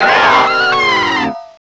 pokeemerald / sound / direct_sound_samples / cries / dialga.aif